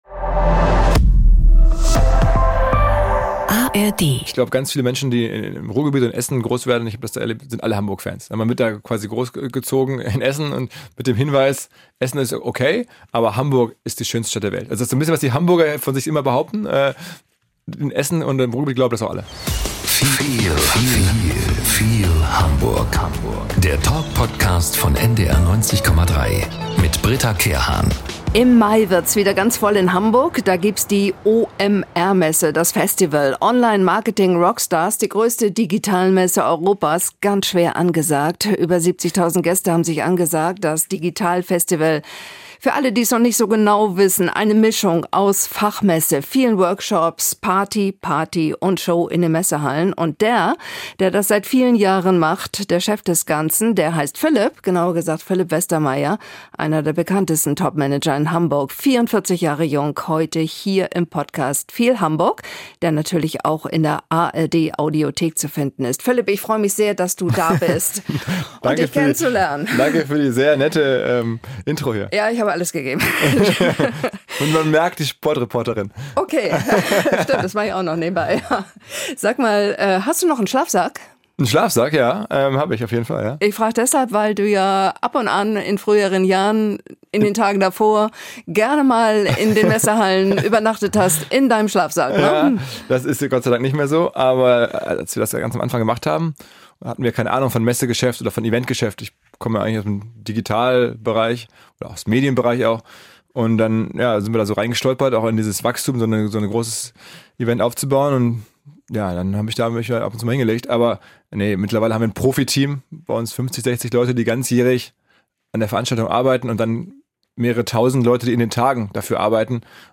Nachrichten - 19.07.2023